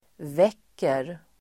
Uttal: [v'ek:er]